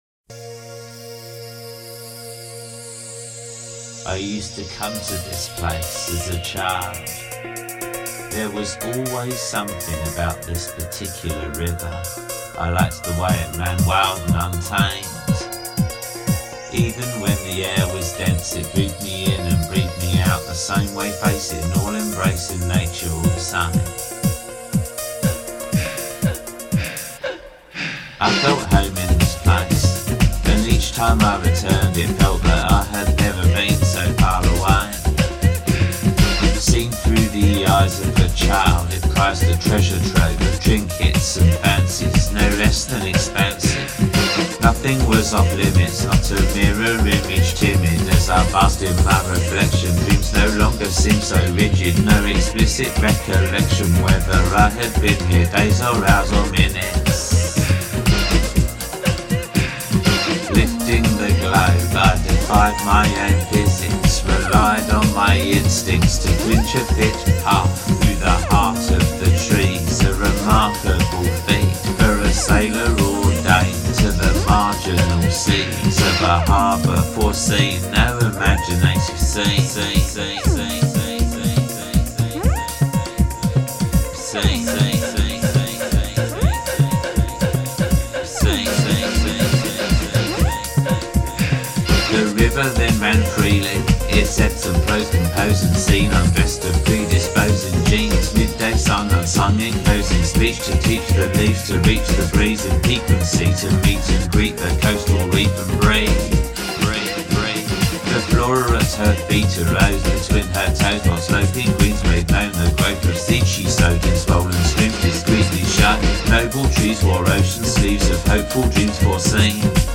River-Song-Salsa-House-Suite.mp3